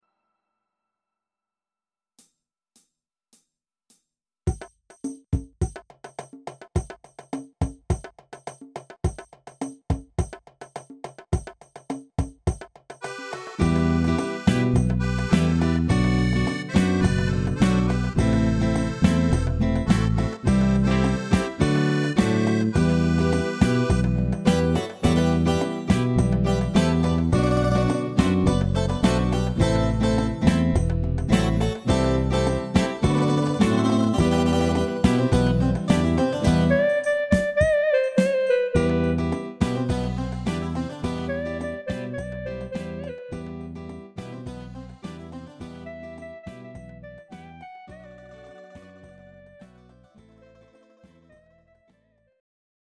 Greek Bolero